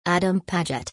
Name Pronunciation: